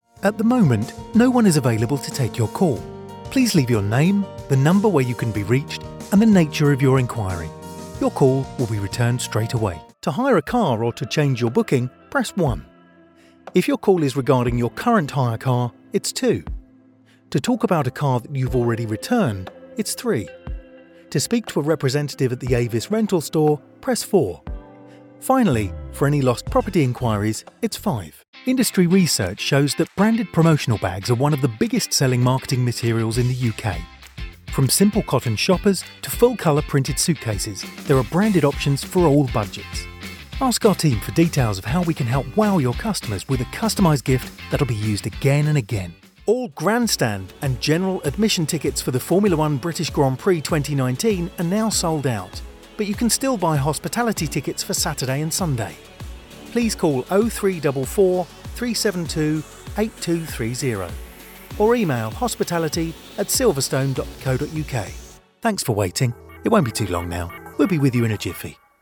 une voix de baryton britannique chaleureuse et polyvalente
Studio indépendant construit par des professionnels, avec cabine isolée, ce qui signifie que je peux être disponible pour enregistrer 24h/24 et 7j/7, même si mon voisin utilise le souffleur de feuilles !